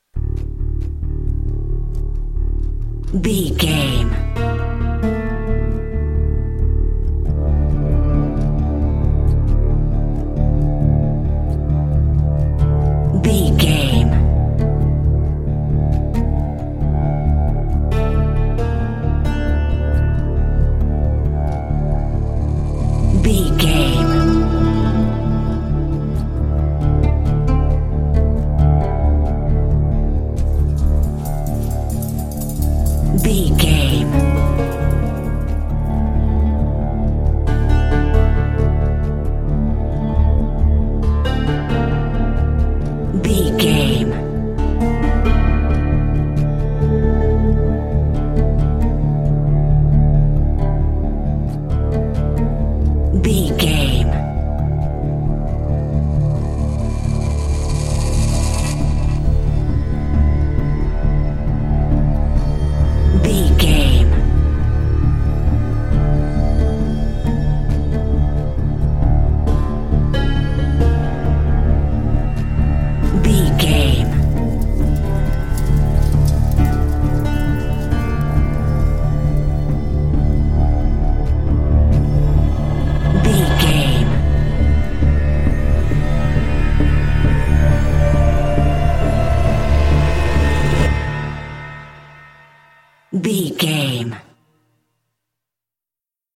Aeolian/Minor
synthesiser
percussion